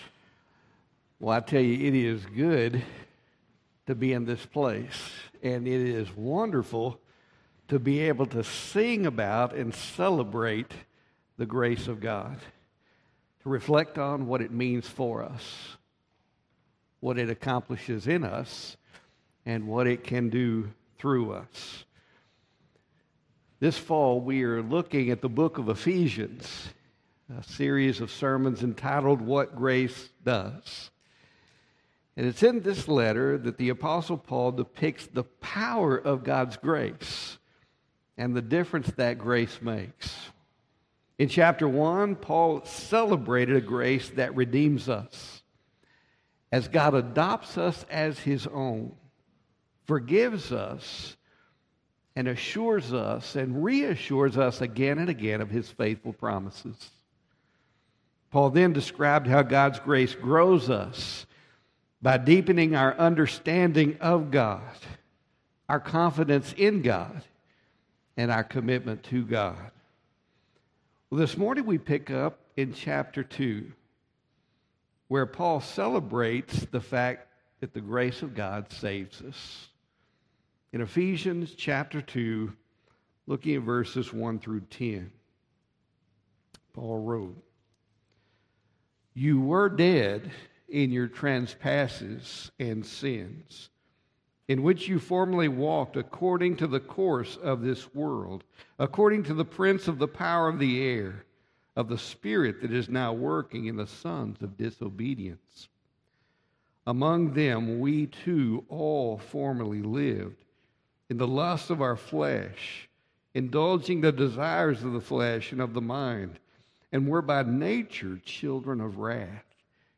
Sermons | First Baptist Church Brownwood